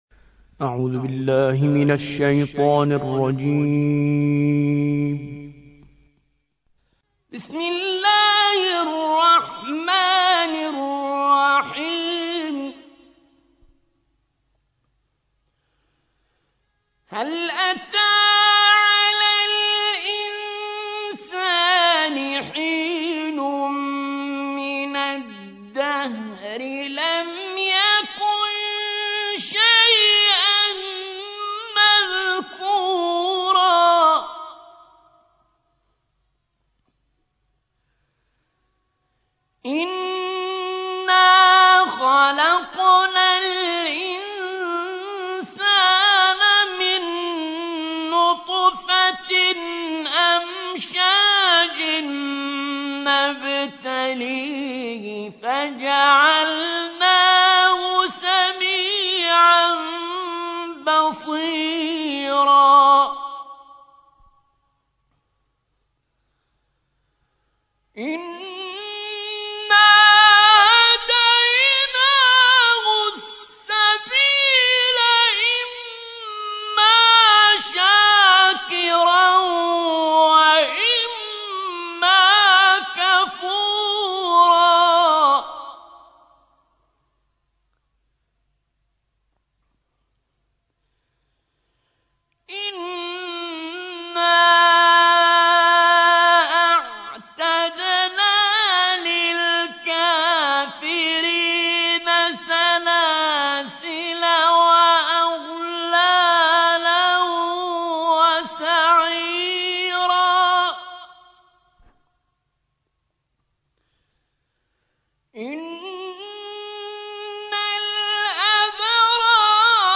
76. سورة الإنسان / القارئ